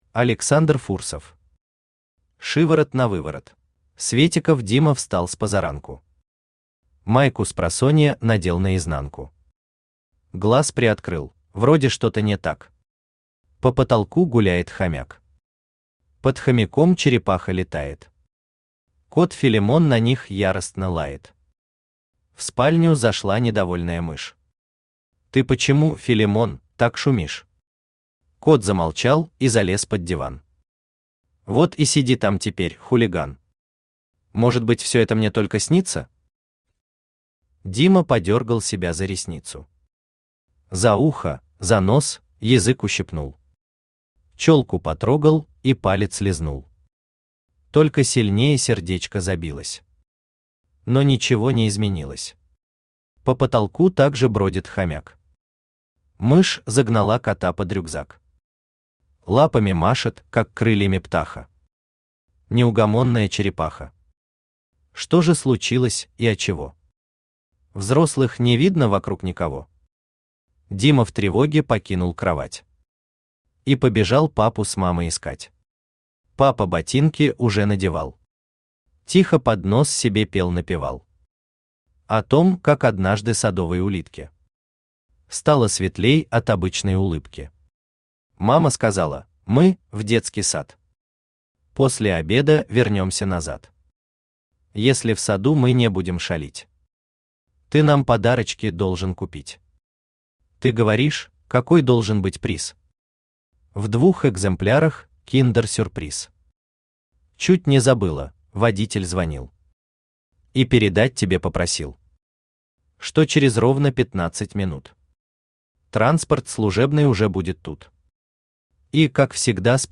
Аудиокнига Шиворот-навыворот | Библиотека аудиокниг
Aудиокнига Шиворот-навыворот Автор Александр Иванович Фурсов Читает аудиокнигу Авточтец ЛитРес.